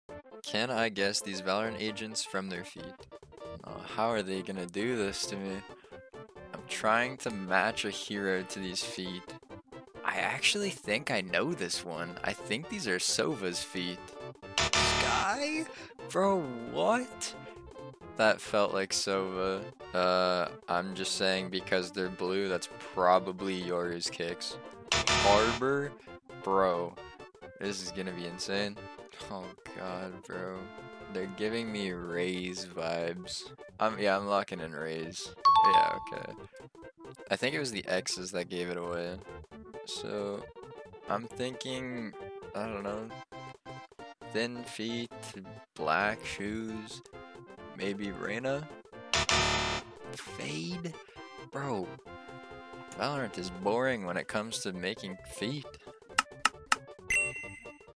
Guessing Valorant Agents From Their Sound Effects Free Download